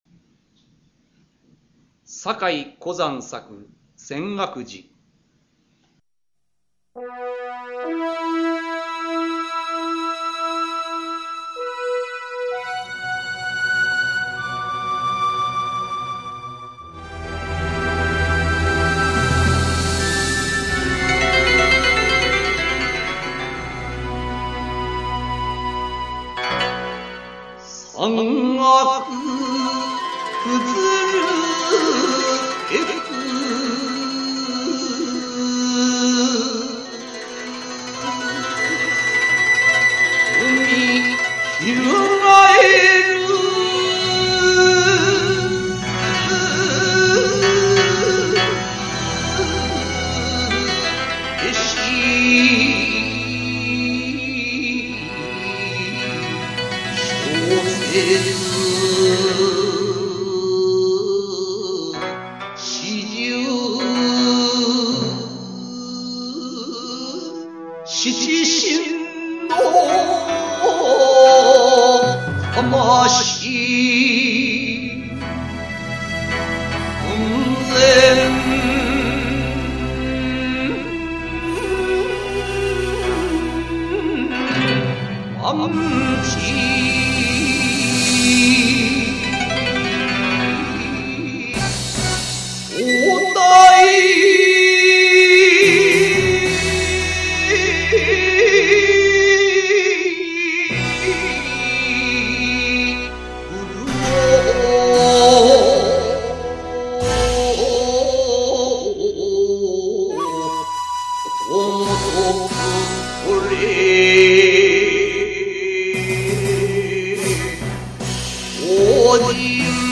漢詩紹介